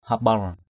/ha-ɓar/